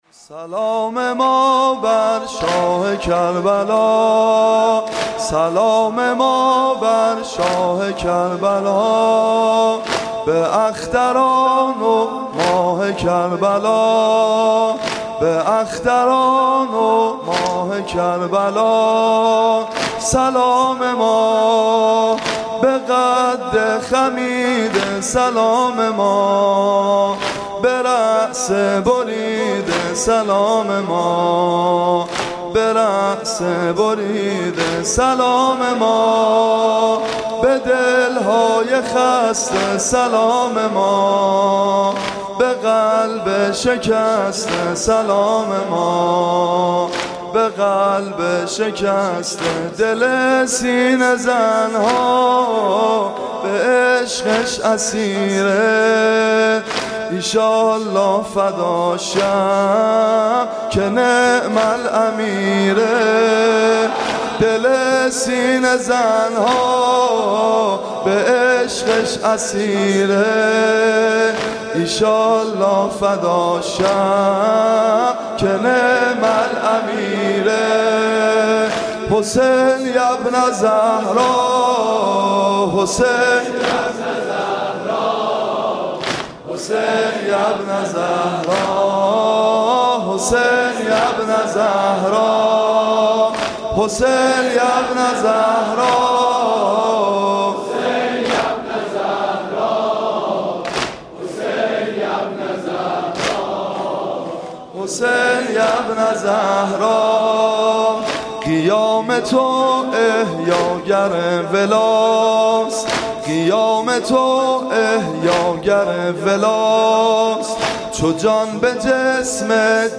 مداحی
صوت مراسم شب سوم محرم ۱۴۳۷ هیئت ابن الرضا(ع) ذیلاً می‌آید: